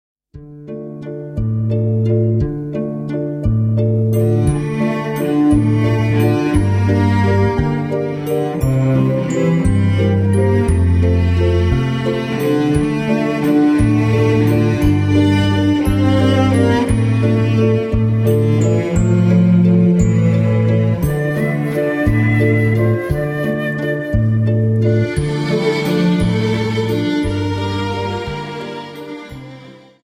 Dance: Viennese Waltz